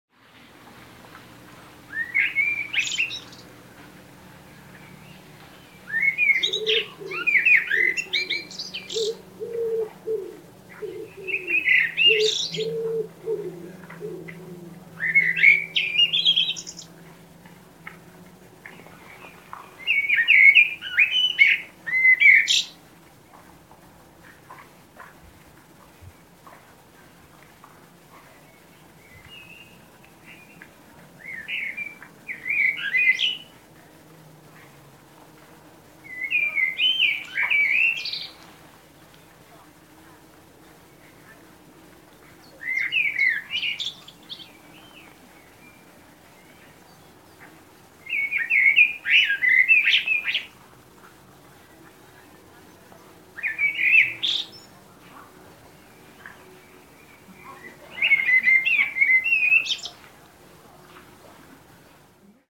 Common Blackbird song,Svarttrost,Merle Noir Chant,Il sound effects free download